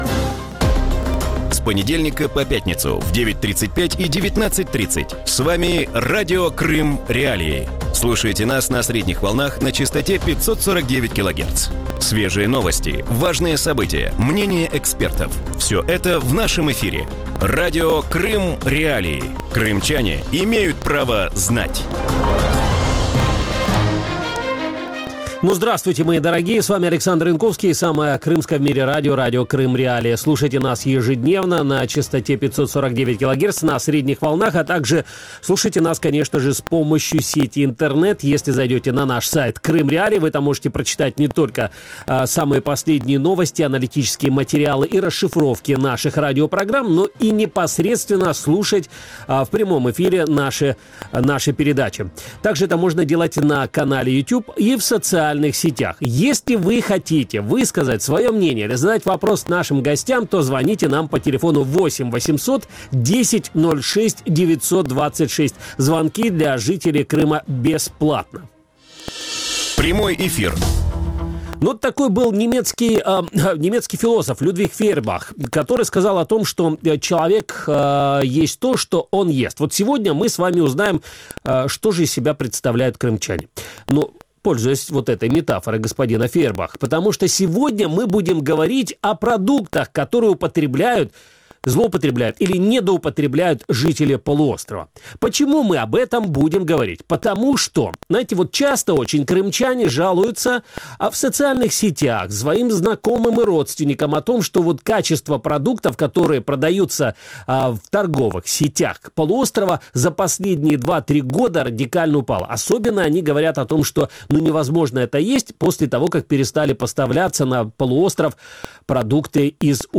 В вечернем эфире Радио Крым.Реалии говорят о качестве продуктов питания на полках крымских магазинов. Как изменилось их качество после аннексии полуострова, что добавляют в продукты российские предприятия для удешевления продукции и как некачественные продукты влияют на здоровье?